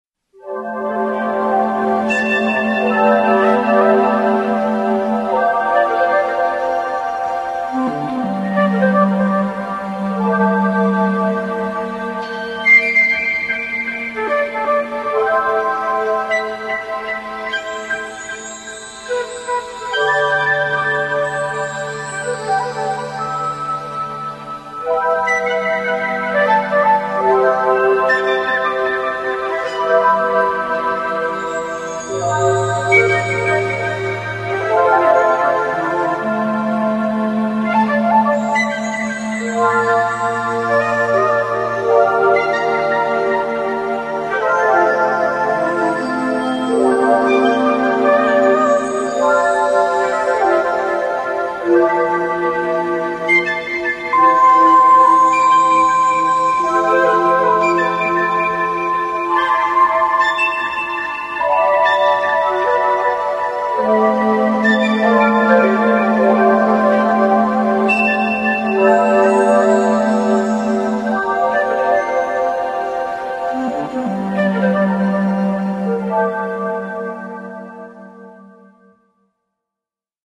Tags: epcot horizons audio clips themepark audio